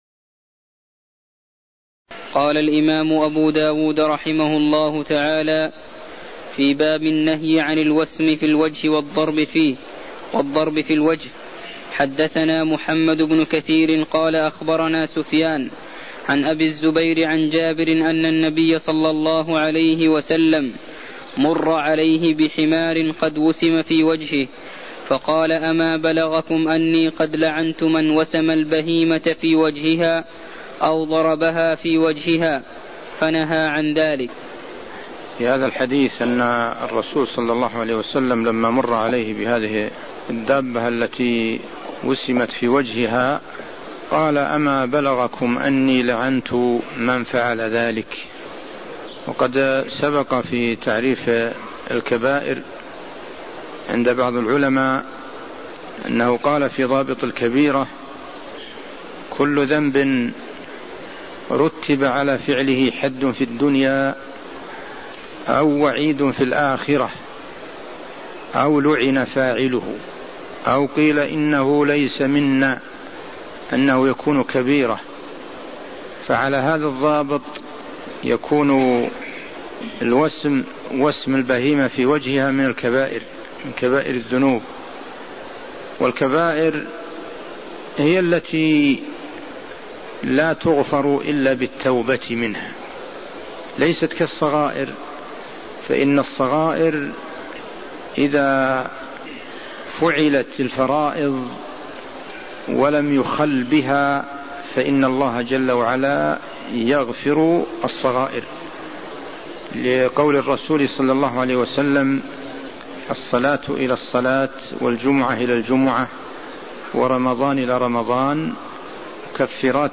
المكان: المسجد النبوي الشيخ: عبدالله الغنيمان عبدالله الغنيمان باب النهي عن الوسم في الوجه باب السبق على الرجل (10)